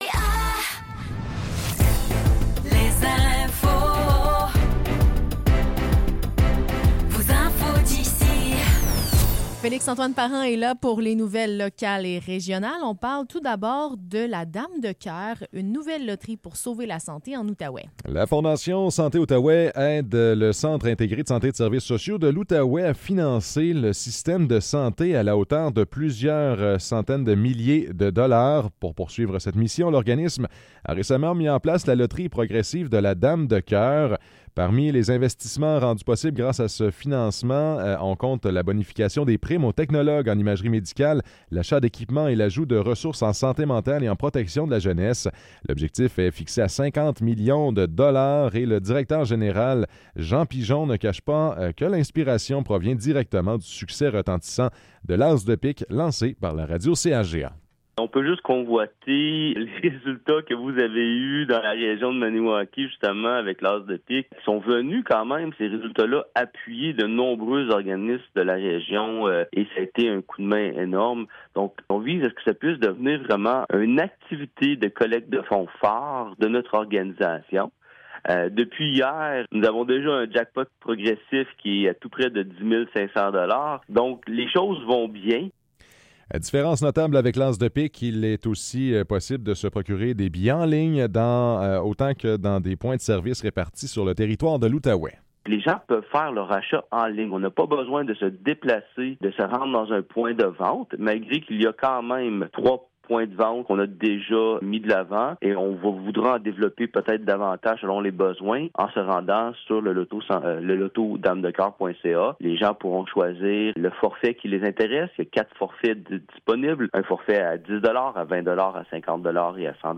Nouvelles locales - 9 janvier 2025 - 10 h